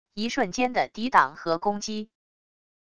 一瞬间的抵挡和攻击wav音频